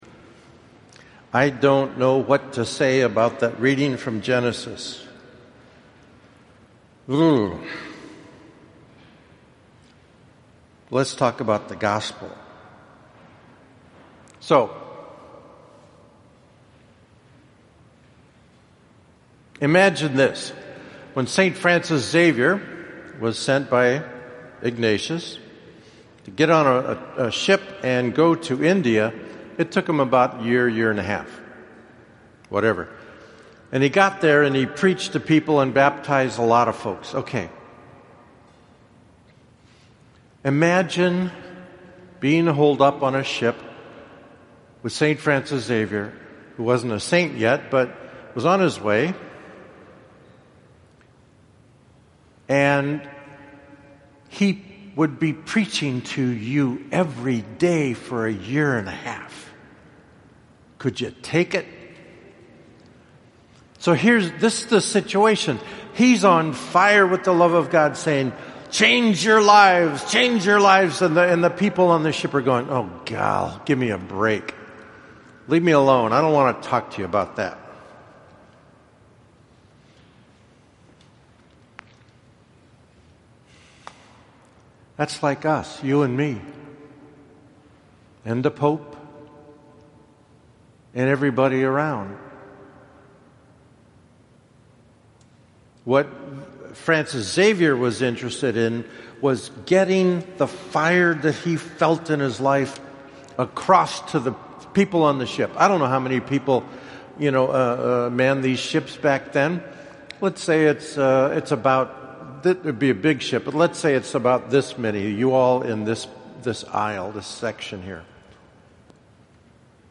Here’s the audio file of my homily(It was posted late) about the Transfiguration and the ‘place’ of the disciples therein.